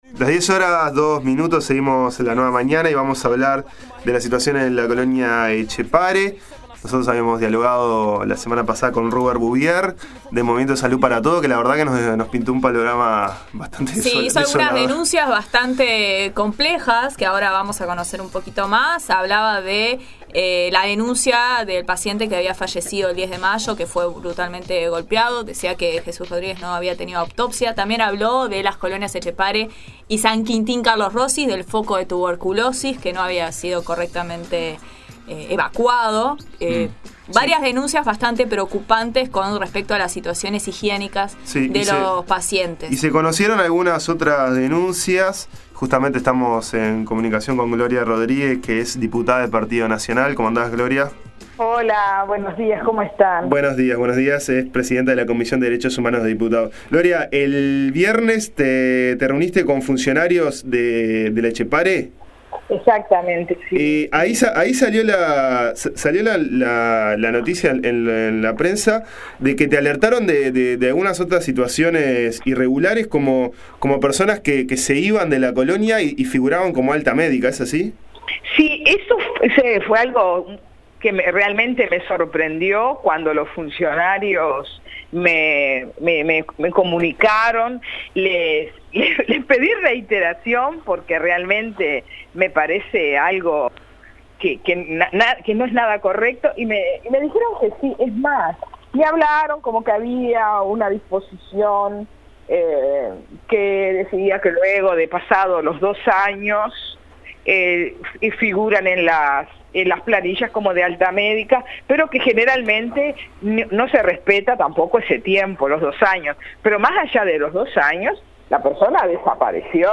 Conversamos con Gloria Rodríguez, presidenta de la Comisión de Derechos Humanos de Diputados, quien recibió el viernes pasado en forma reservada a un grupo de funcionarios que le alertaron sobre el descontrol que se vive en las colonias psiquiátricas de San José.